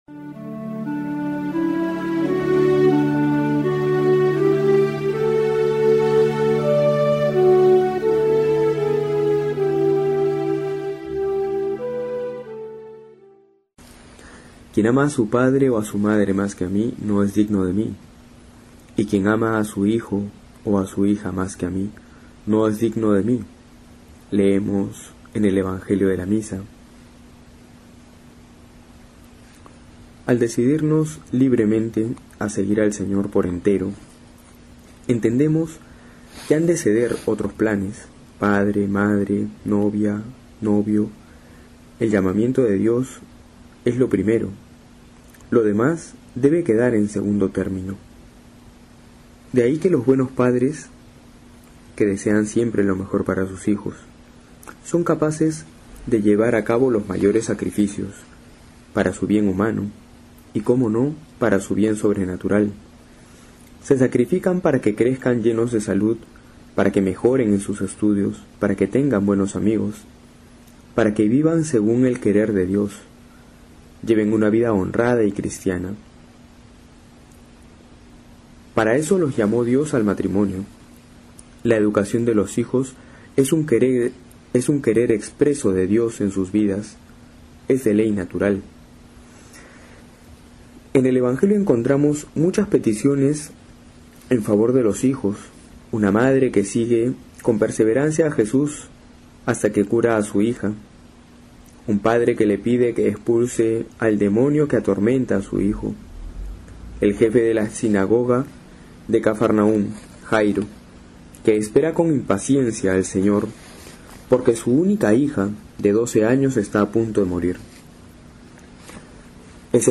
Homilía para hoy: Mateo 10,34-11,1
julio15-13homilia.mp3